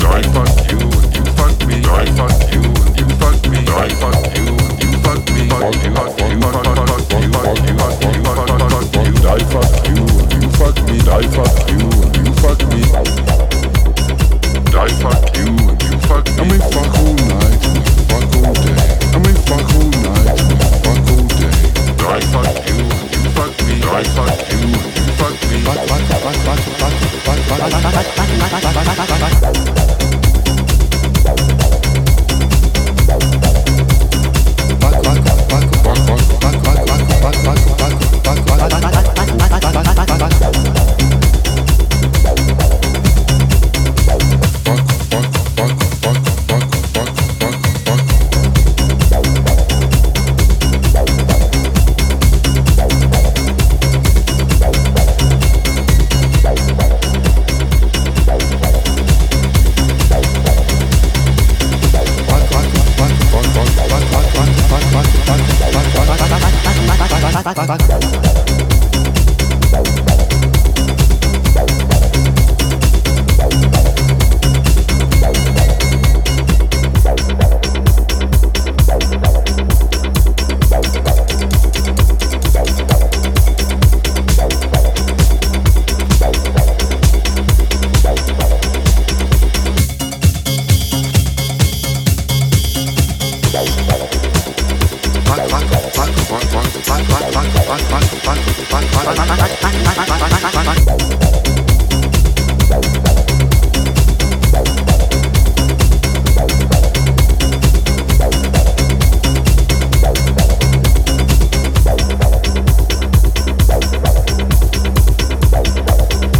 A面2曲では特にギリギリのユーモアが発揮され、フロアに白熱と苦笑の両方をもたらすこと間違い無しです。